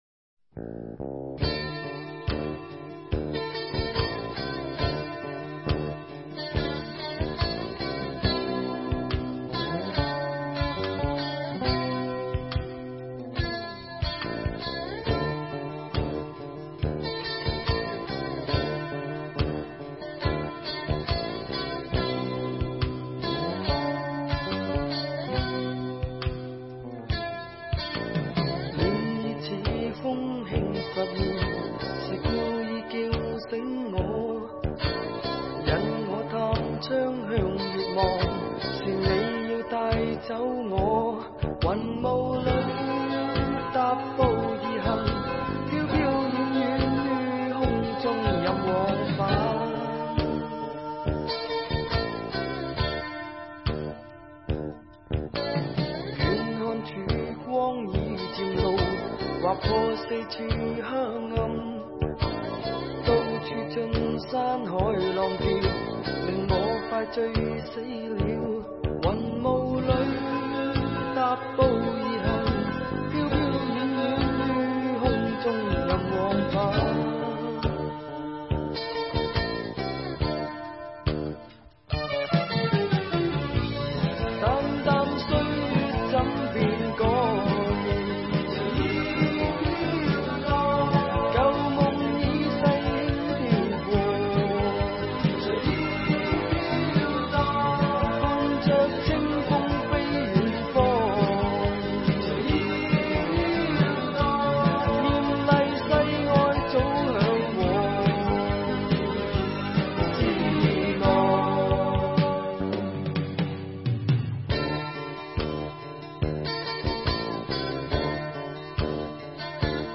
• 既有优美的旋律，
• 更难得的是此曲带有浓烈的流行摇摆感觉。